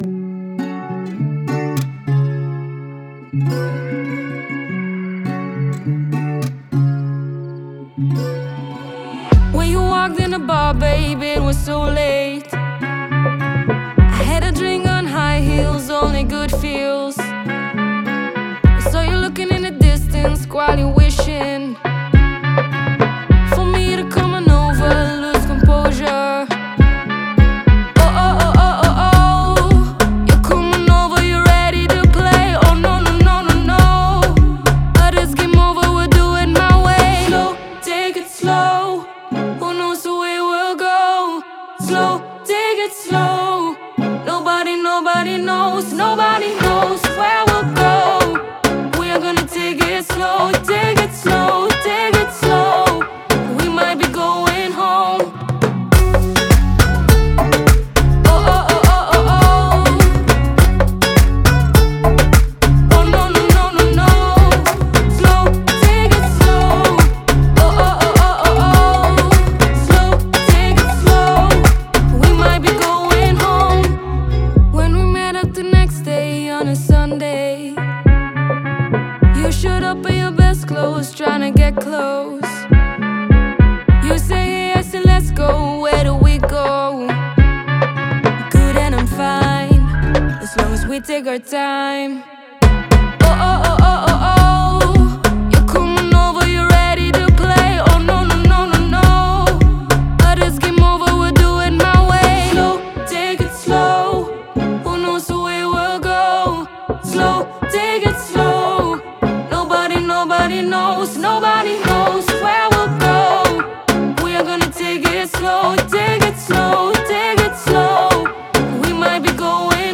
Яркое вокальное исполнение